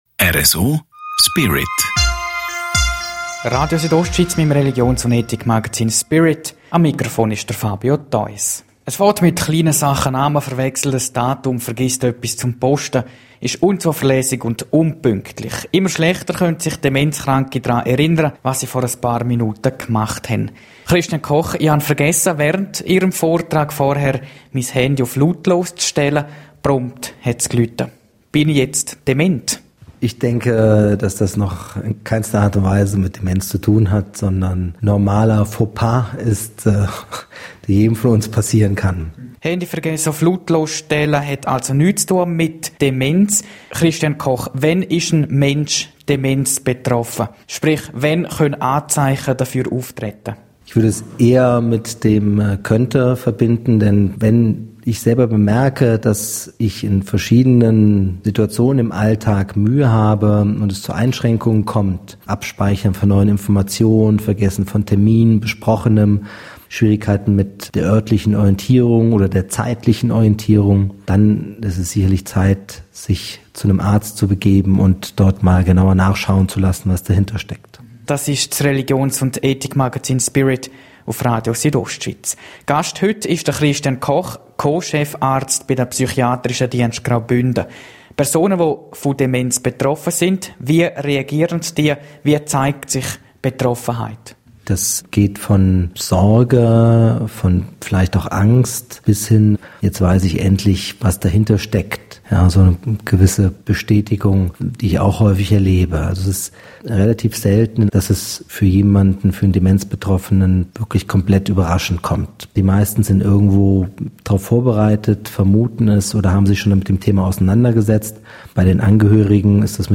Leben & Freizeit